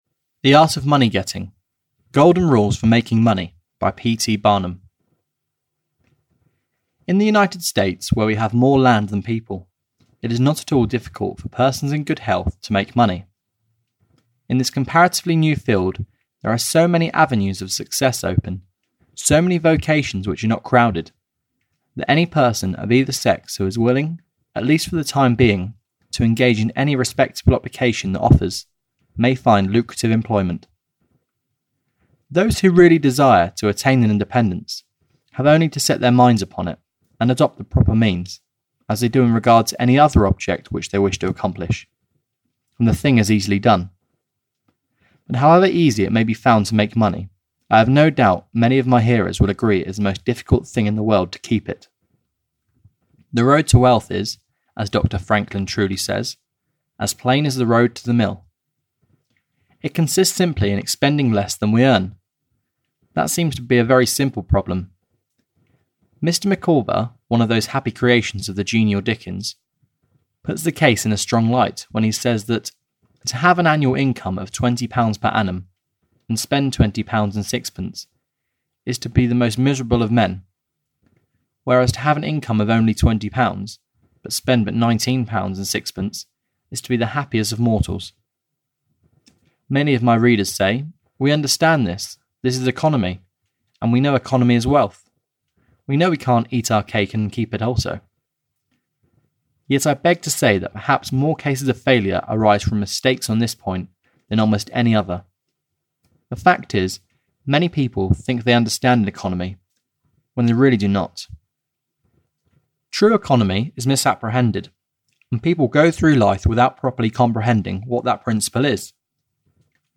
The Art of Money Getting (EN) audiokniha
Ukázka z knihy